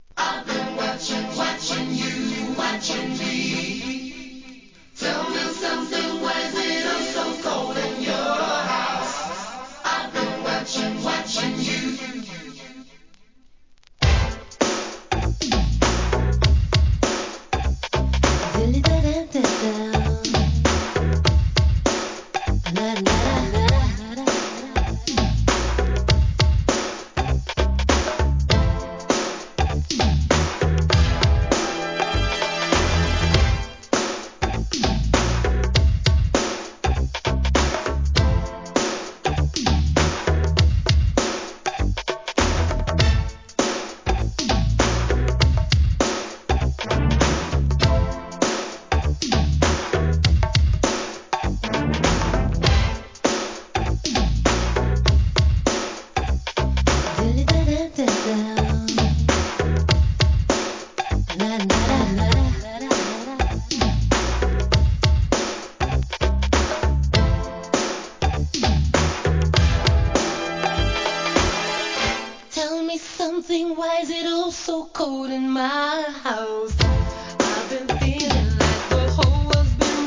HIP HOP/R&B
1988年、ミディアムテンポで跳ねるUK SOUL!